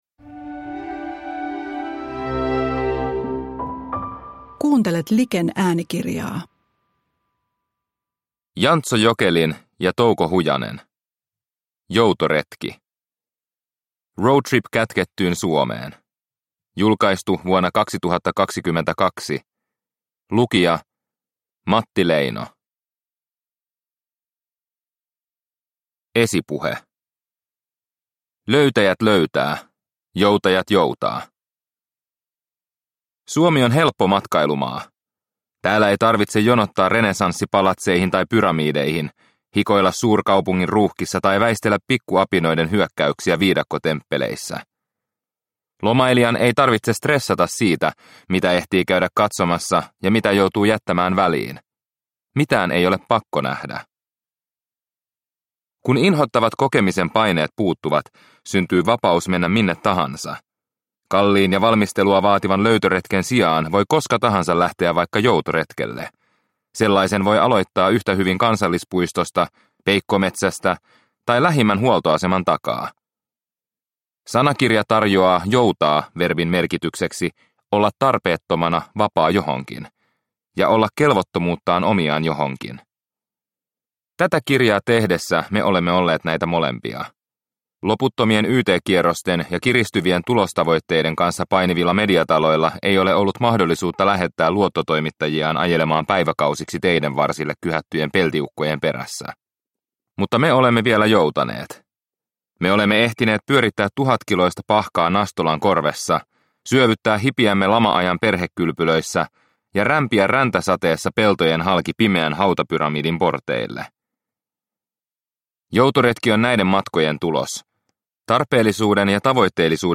Joutoretki – Ljudbok – Laddas ner